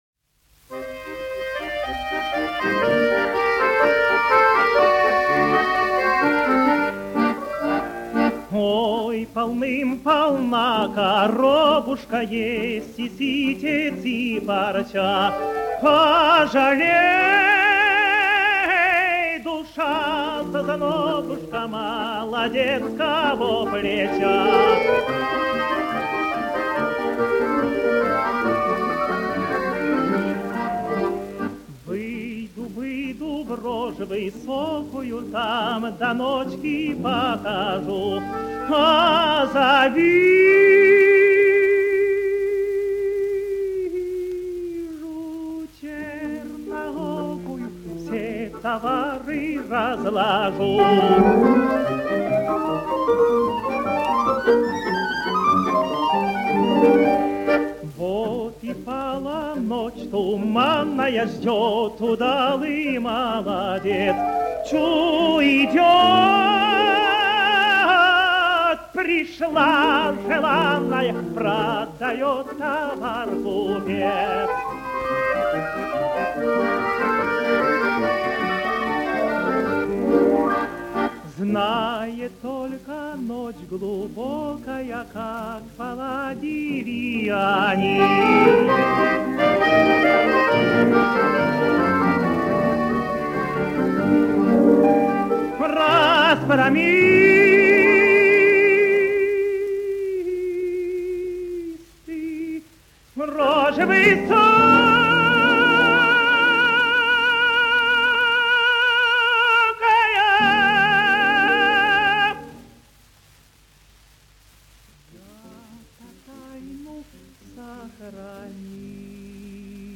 русская народная песня Слова